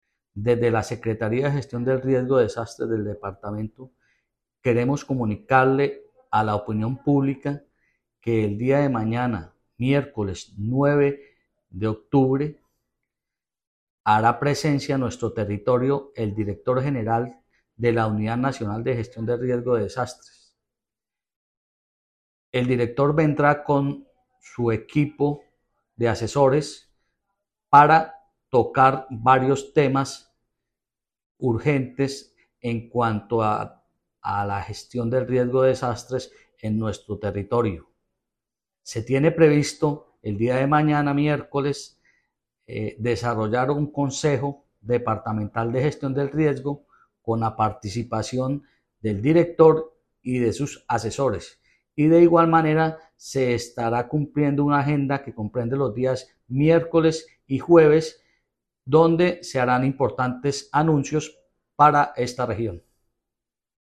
Audio-de-William-Vera-secretario-de-Riesgos.mp3